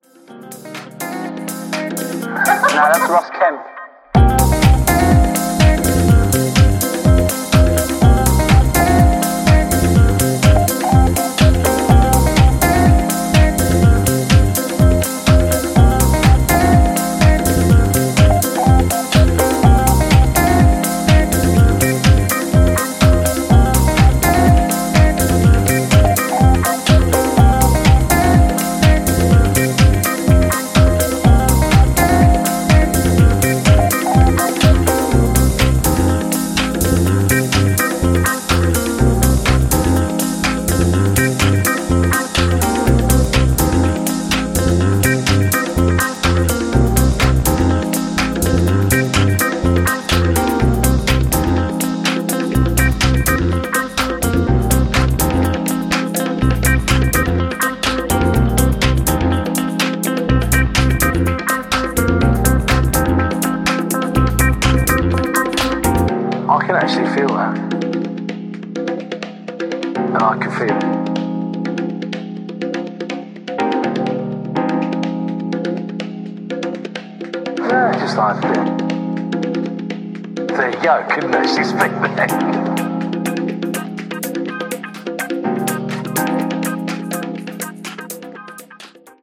ニューディスコ、ディスコ、ファンク、といったジャンルをファンキーなソウルフルハウスで仕上げたスペシャルなトラック全6曲！
ジャンル(スタイル) NU DISCO / DISCO / HOUSE